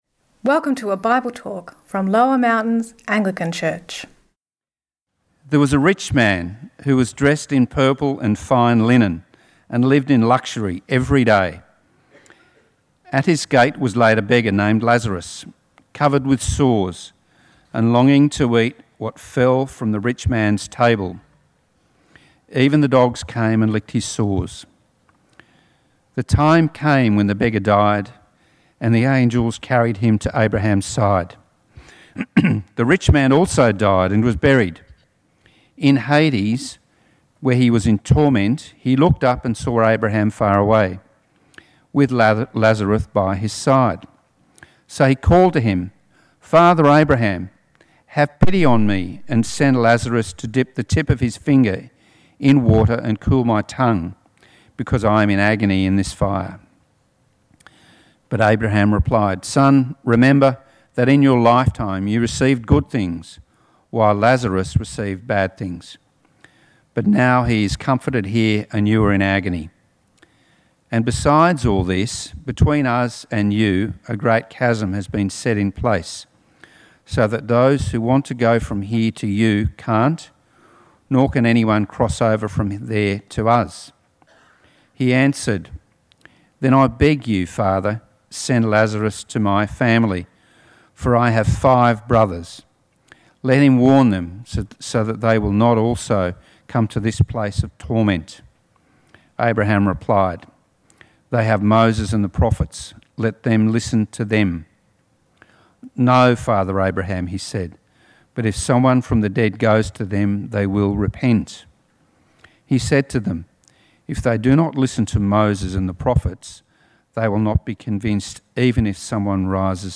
Sermon – If loving, God why send people to hell? (Luke 16:19-31)